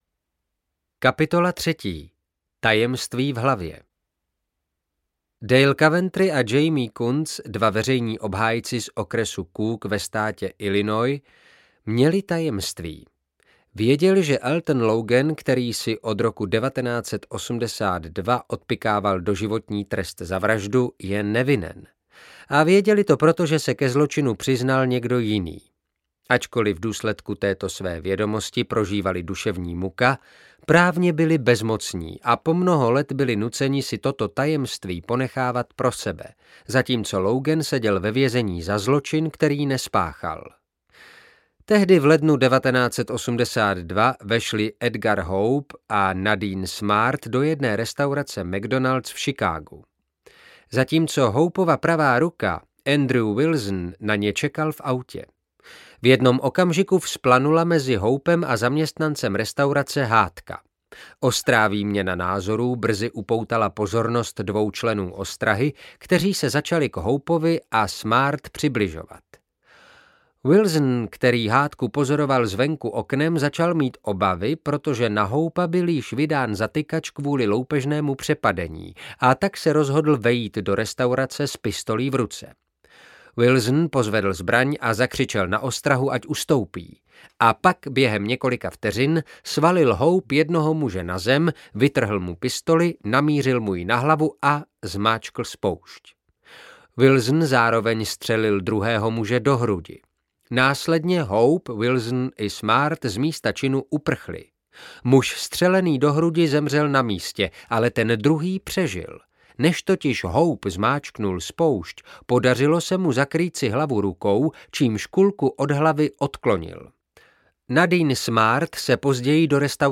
Tajný život našich tajemství audiokniha
Ukázka z knihy